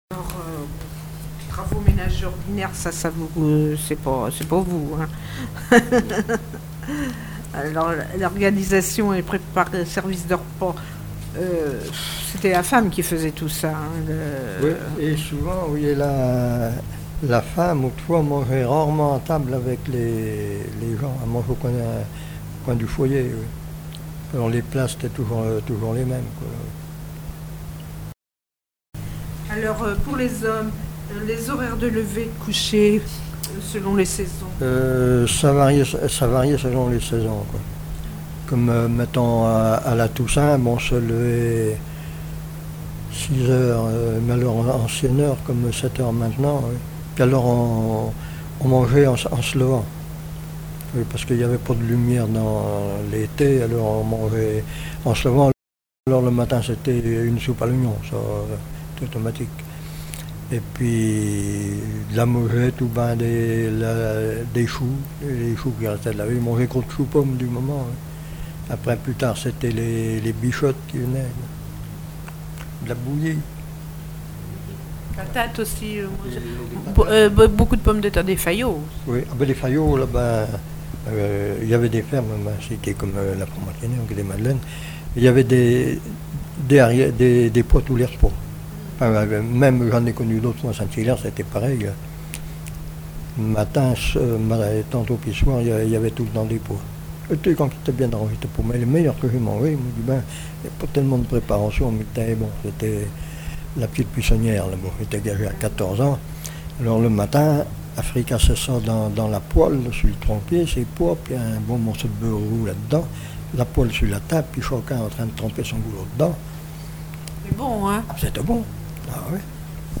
Témoignages de vie
Catégorie Témoignage